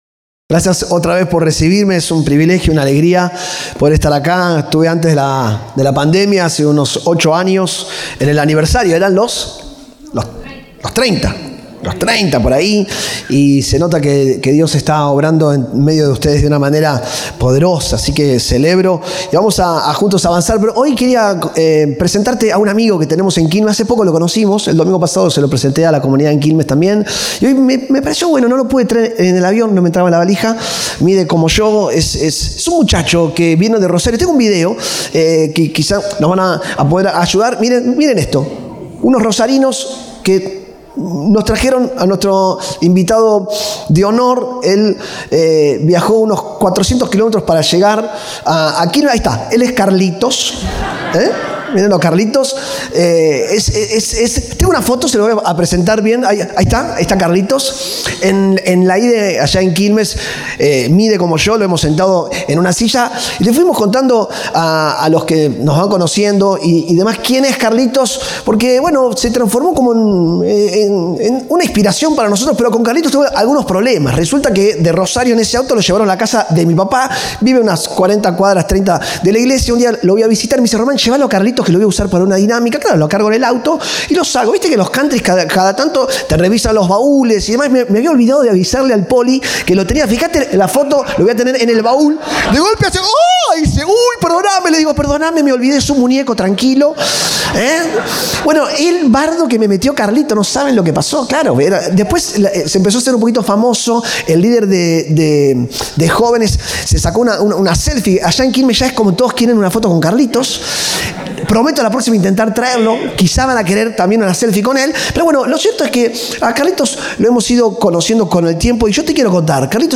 Compartimos el mensaje del Domingo 22 de Junio de 2025.